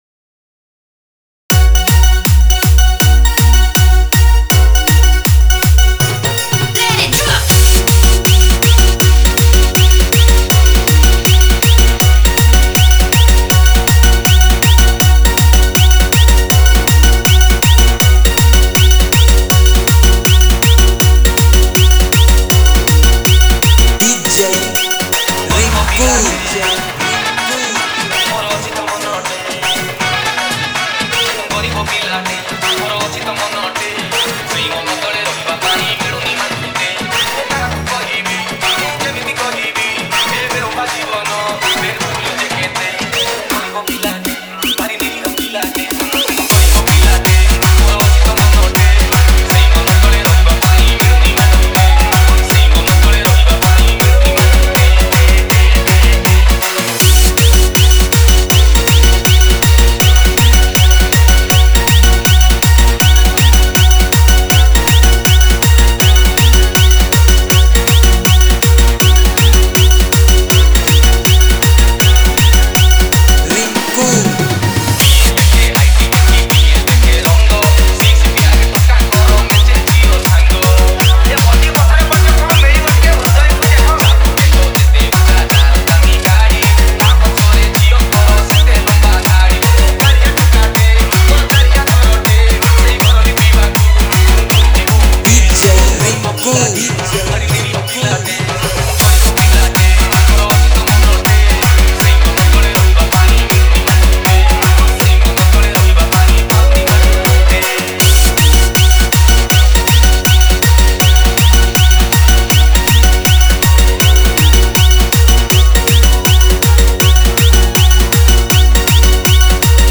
Category:  New Odia Dj Song 2022
Odia Dance Dj Song